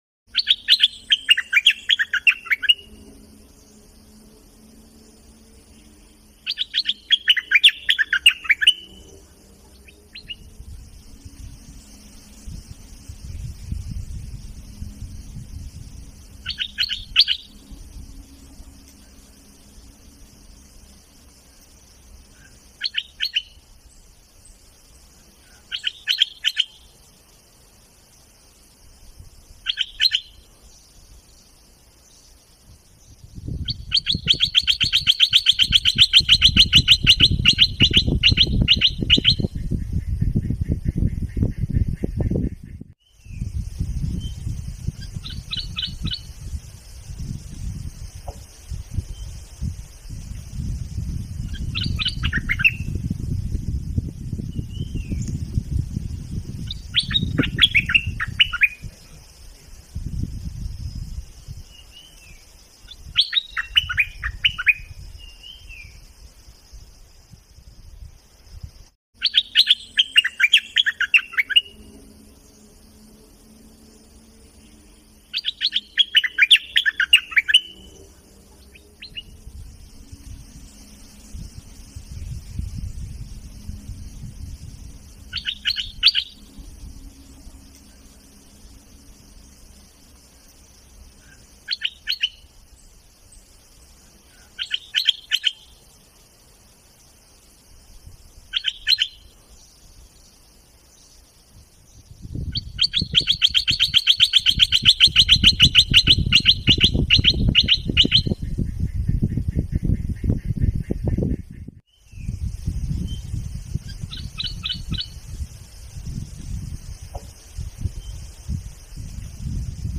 โหลดเสียงนกดอกแตง mp3
เสียงนกดอกแตง
แท็ก: เสียงนกดอกแตง เสียงนกปรอดสวน เสียงนกป่า